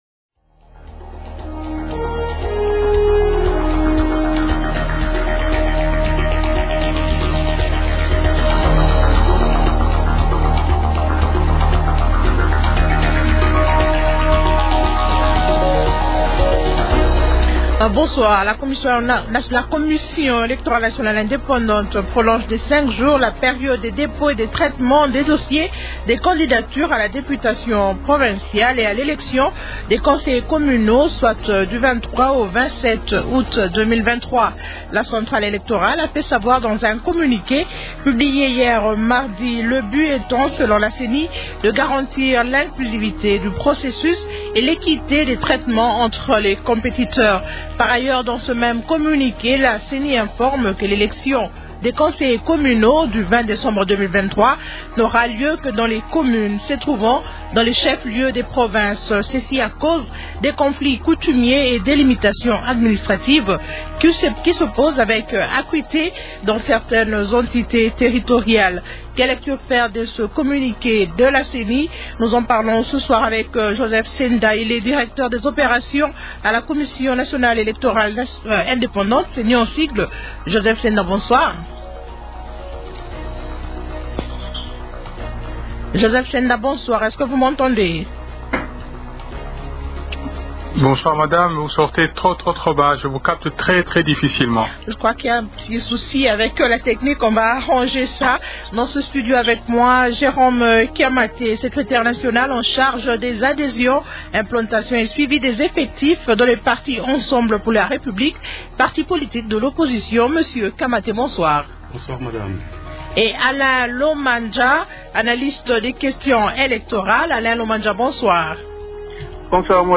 analyste des questions électorales.